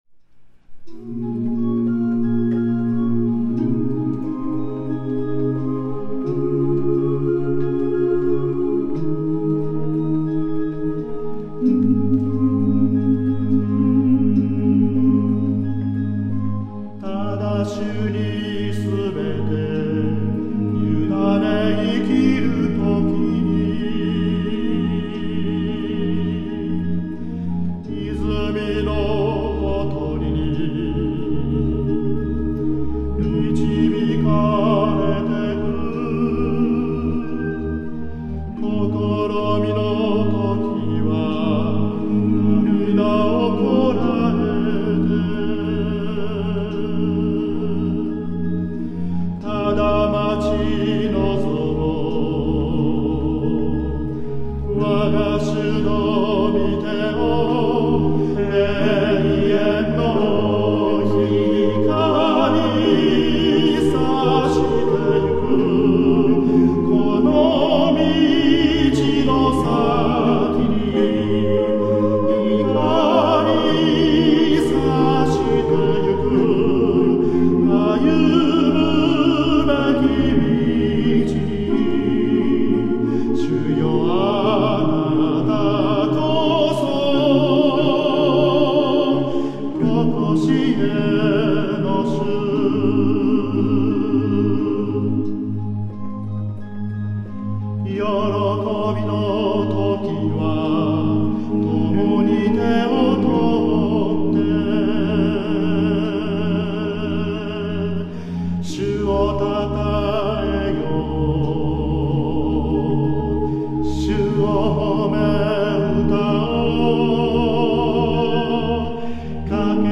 主婦・学生・社会人などから構成されています。
現在隊員は約40名で、混声四部です。
MP3が再生できる環境でしたら、黎明聖歌隊の演奏を聞くことができます。